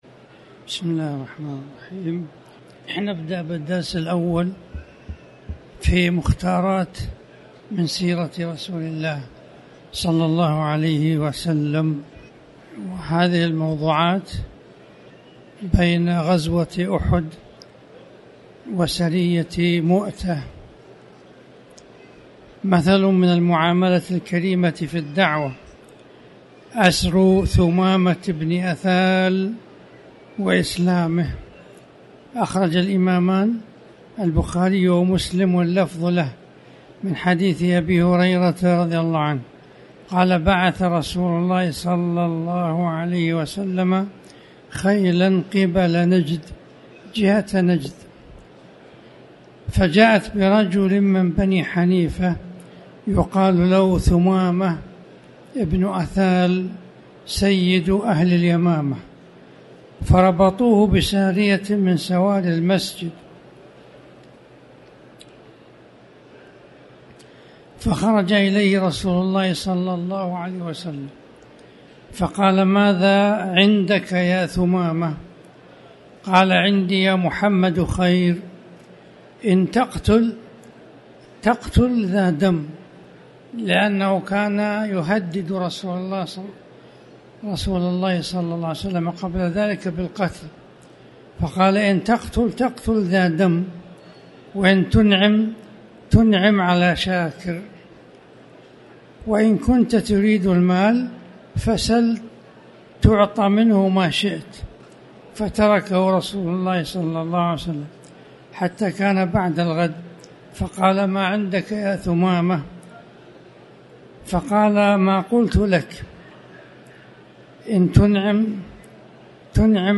تاريخ النشر ٧ شعبان ١٤٣٩ هـ المكان: المسجد الحرام الشيخ